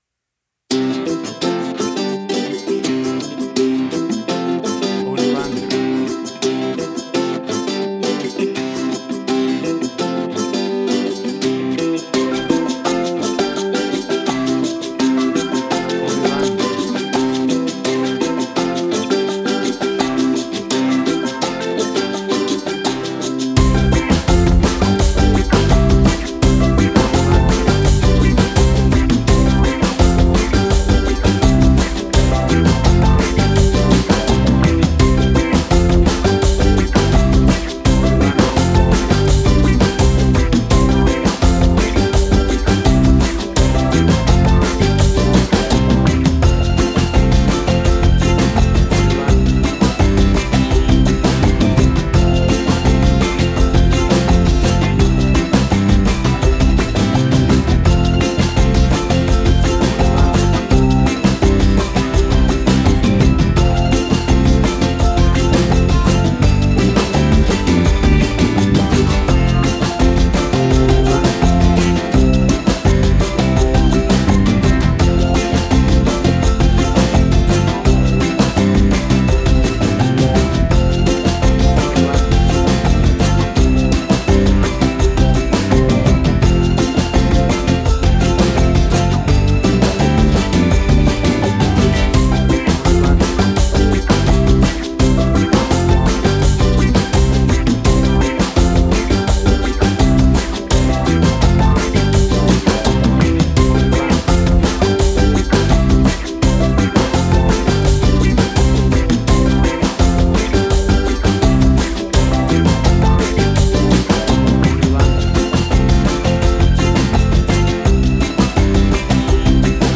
Tempo (BPM): 84